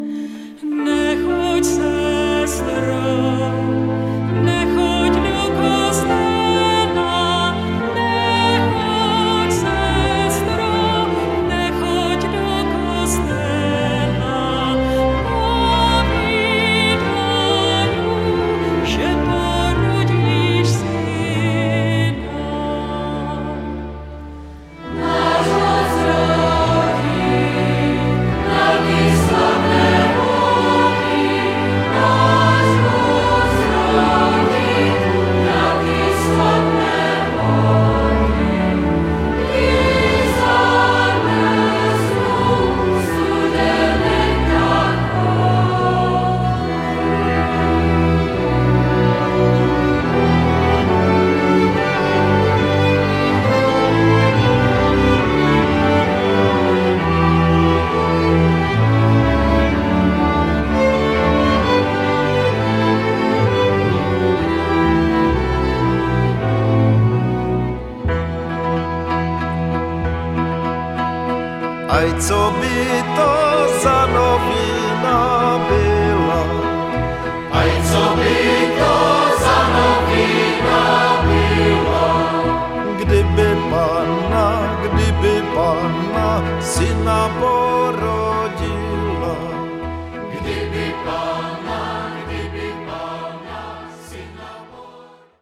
lidová
zpěv